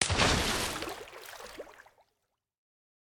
Minecraft Version Minecraft Version snapshot Latest Release | Latest Snapshot snapshot / assets / minecraft / sounds / mob / dolphin / splash3.ogg Compare With Compare With Latest Release | Latest Snapshot
splash3.ogg